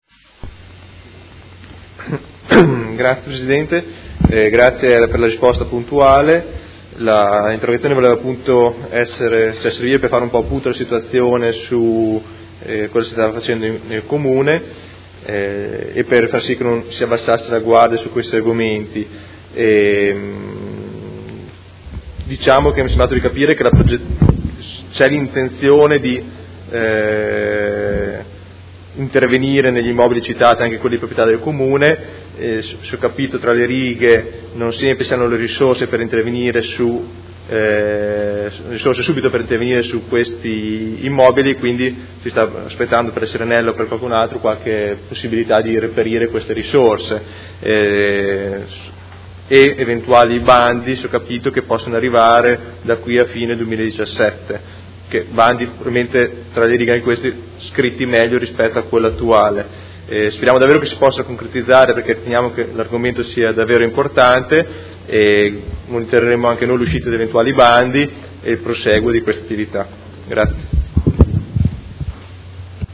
Seduta del 16/03/2017. Conclude interrogazione del Gruppo Movimento cinque Stelle avente per oggetto: Bando Amianto